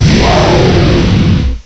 cry_not_volcanion.aif